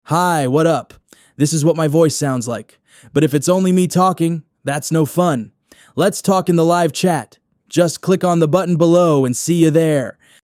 Voice profile
That’s why I speak a little faster and sound energetic and varied.
Male voice
Red-Bull-Stimmprofil-EN-maennlich.mp3